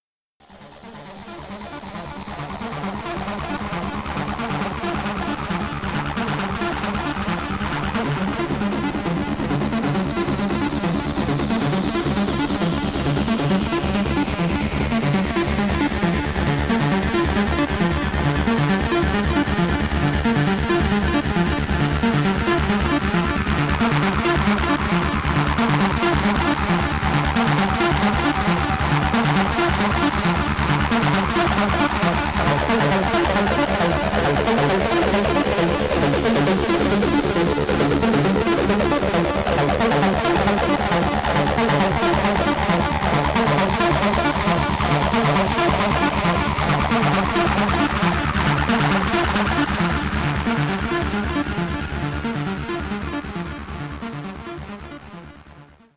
Hardtek/Tekno